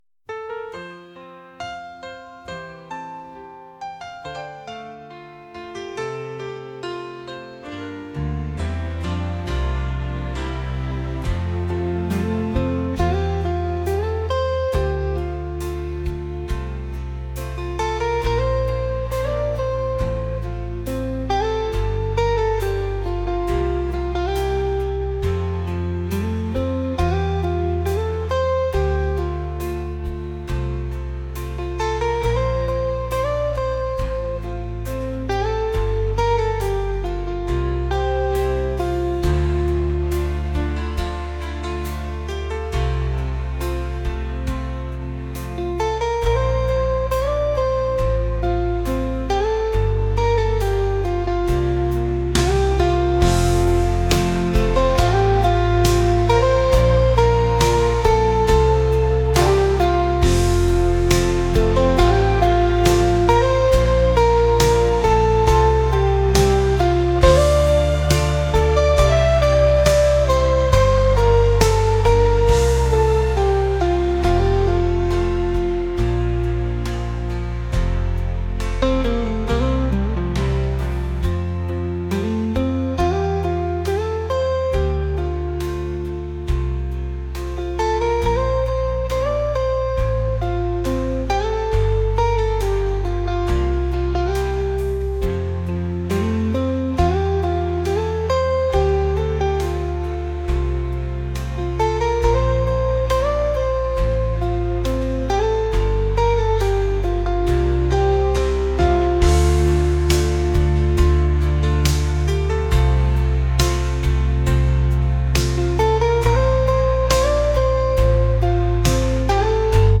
pop | acoustic | soul & rnb